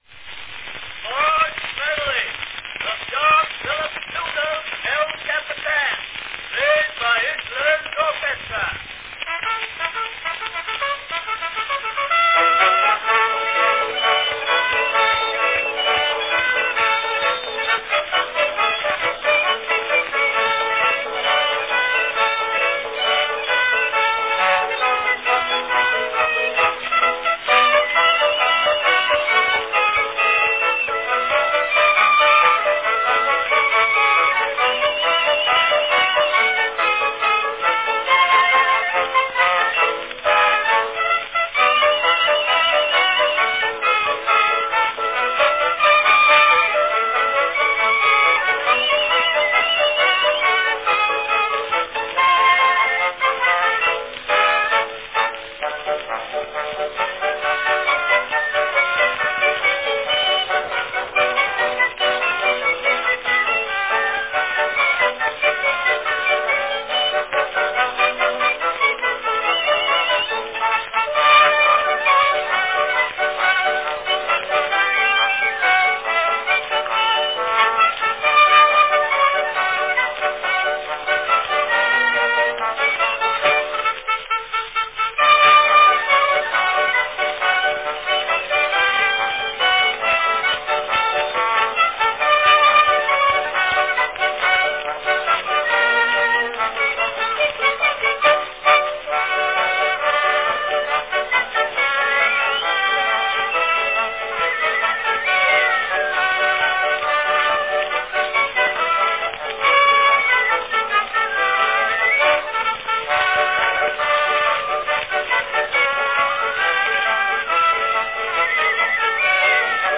a great march medley from Sousa's El Capitan.
Category Orchestra
Announcement "March medley from John Philip Sousa's El Capitan
initially consisting of a piano, cornet, flute and a violin
with their distinctive sound (frequent doubling of cornet with flute or piccolo)